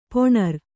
♪ poṇar